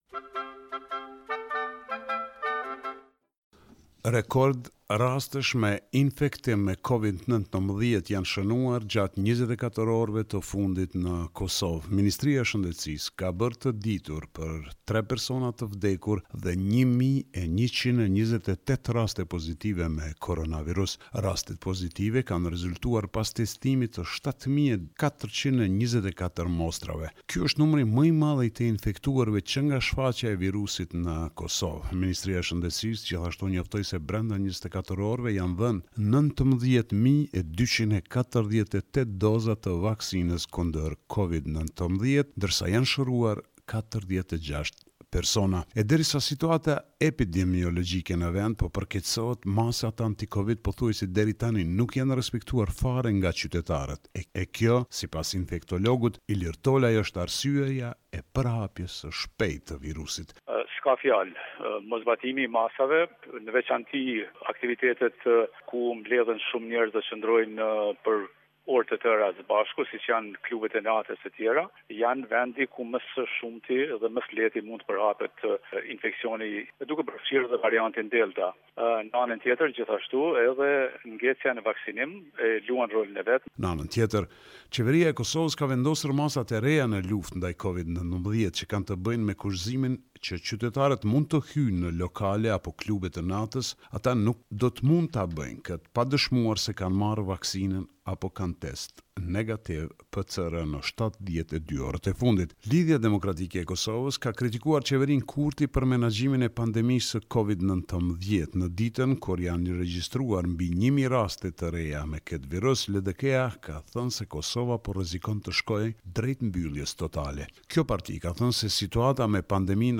This is a report summarising the latest developments in news and current affairs in Kosovo.